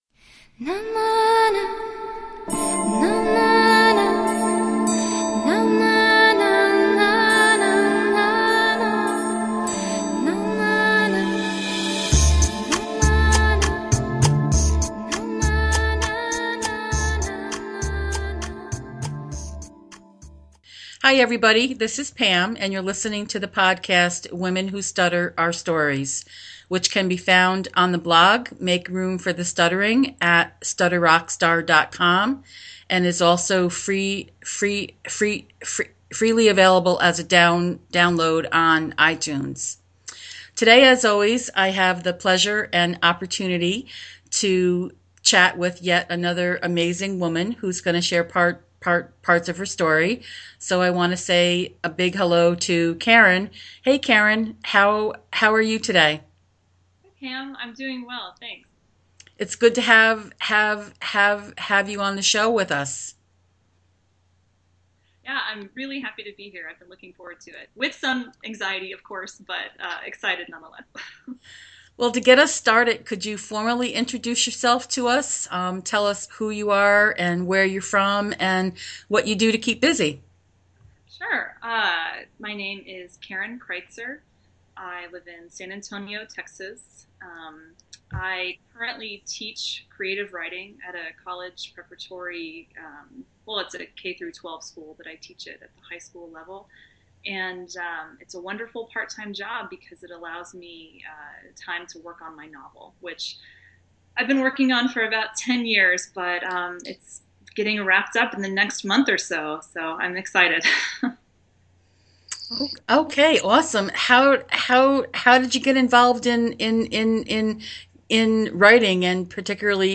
Listen in as we discuss what it is like to be a covert stutterer and how it can take over your whole life. We discussed missed opportunities, “coming out,” acceptance, speech therapy and so much more.